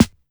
SNARE_ROLL.wav